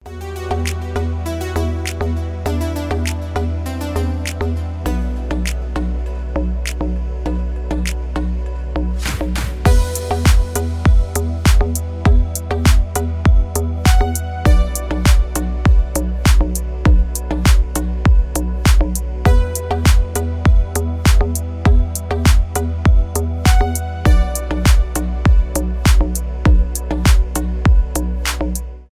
мелодичные , приятные
без слов , танцевальные